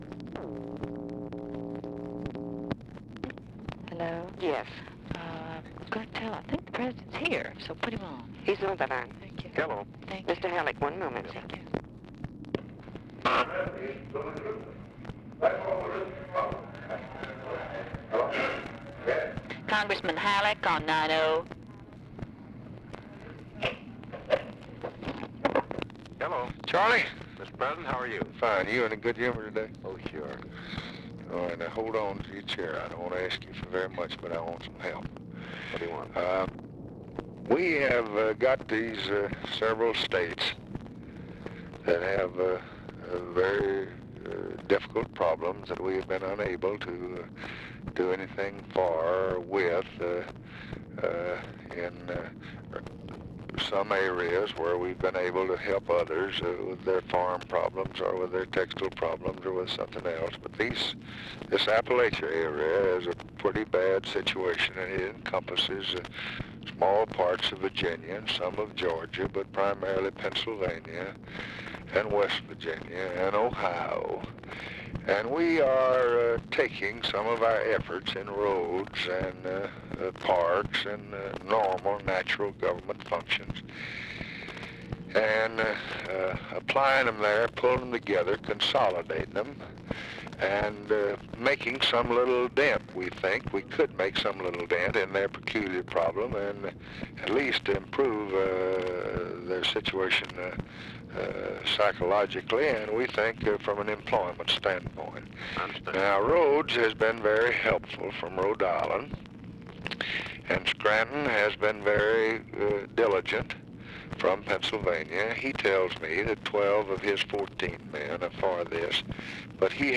Conversation with CHARLES HALLECK and TELEPHONE OPERATOR, October 2, 1964
Secret White House Tapes